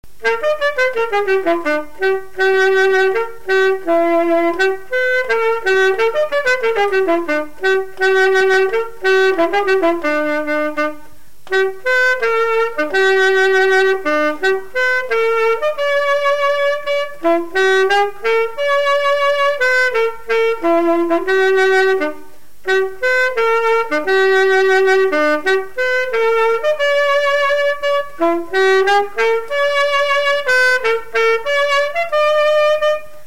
Marche de cortège de noces
Nalliers
Résumé instrumental
Pièce musicale inédite